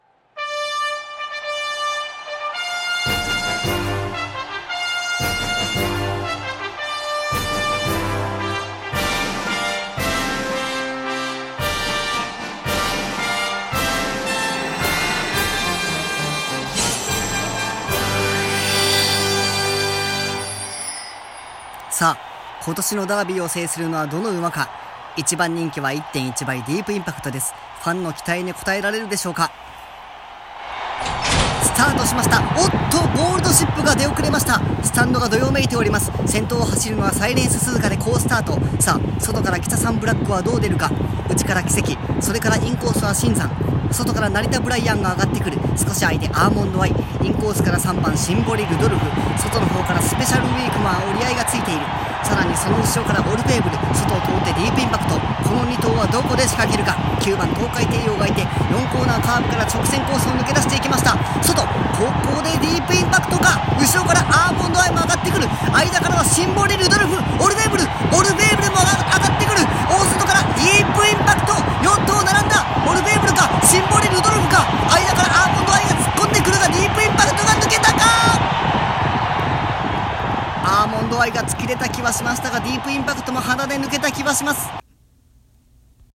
競馬 実況 日本ダービー(東京優駿)